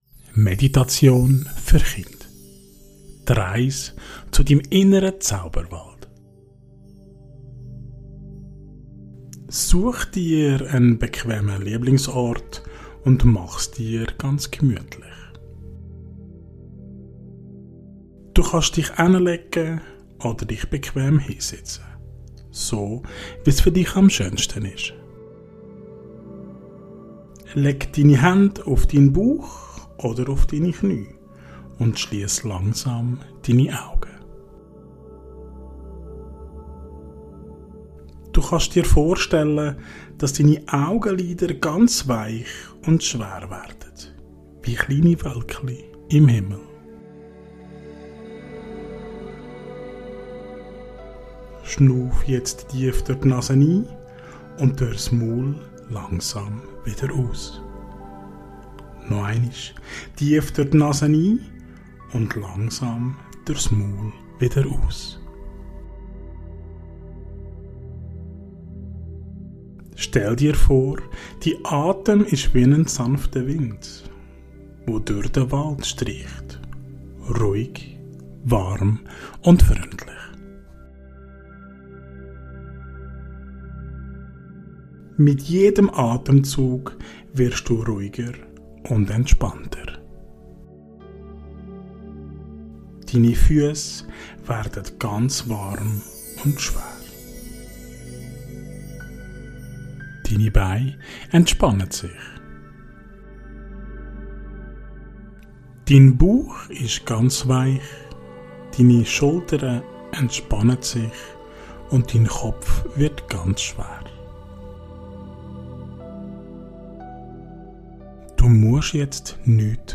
Meditation für Kinder - Die Reise zu deinem inneren Zauberwald ~ Meine Meditationen Podcast